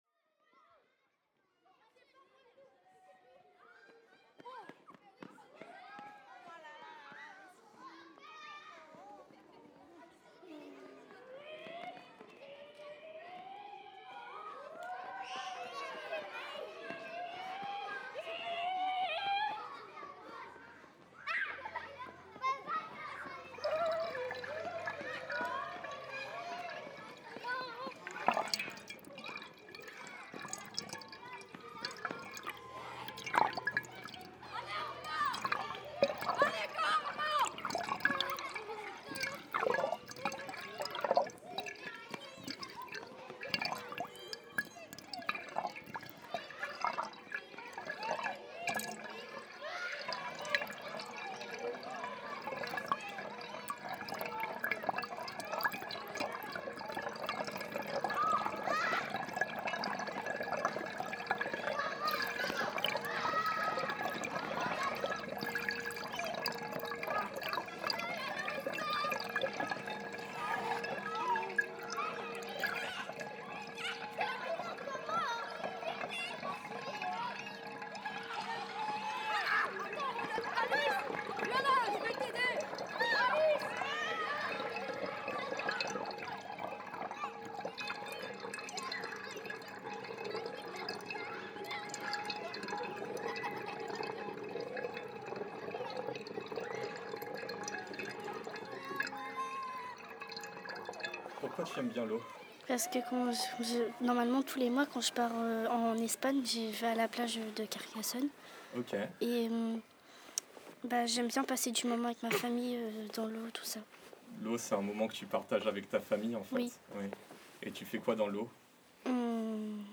Concert autour de l'eau - 21 avril 2025 - Le Bois Frileux